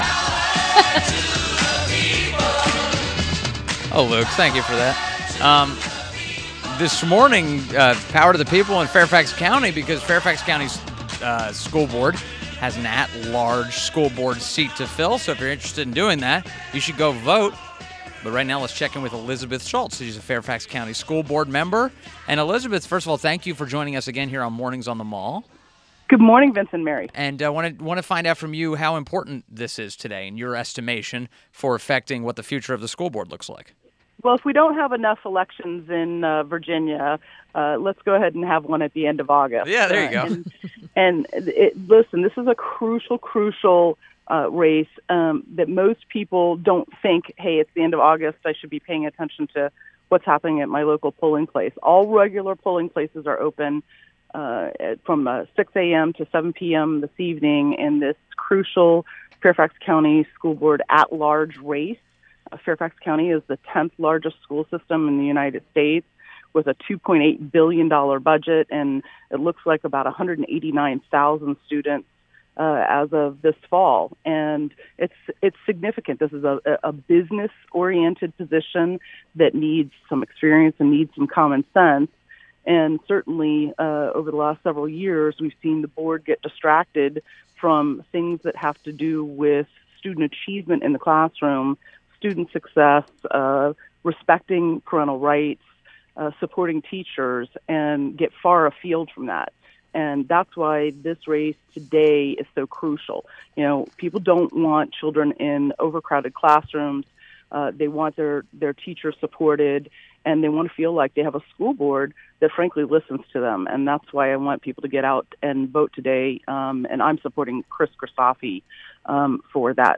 WMAL Interview - ELIZABETH SCHULTZ - 08.29.17
INTERVIEW -- ELIZABETH SCHULTZ - Fairfax County School Board member